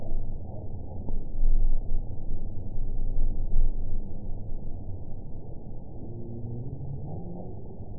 event 914688 date 10/25/22 time 03:38:35 GMT (3 years, 1 month ago) score 5.59 location TSS-AB06 detected by nrw target species NRW annotations +NRW Spectrogram: Frequency (kHz) vs. Time (s) audio not available .wav